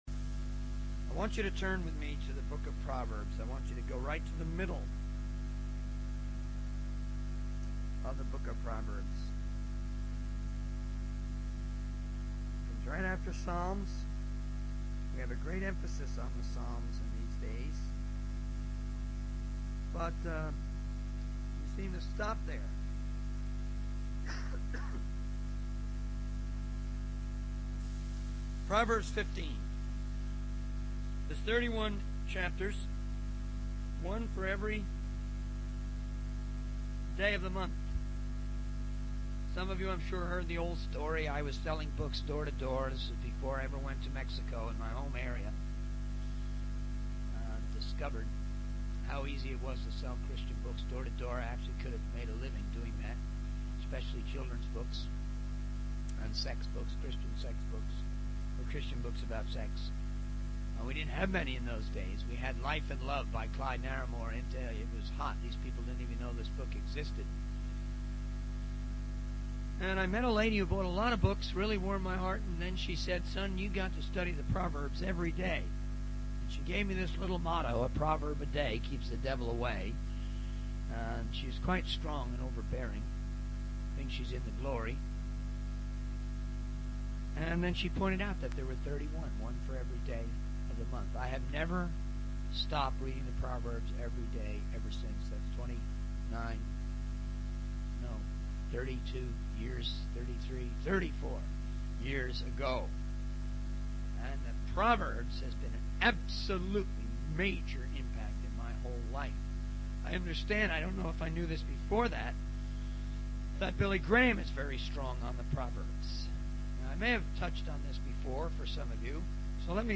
In this sermon, the preacher emphasizes the importance of love in the Christian faith. He shares personal stories of reaching out to people on the ship and how love should be the motivation behind our actions.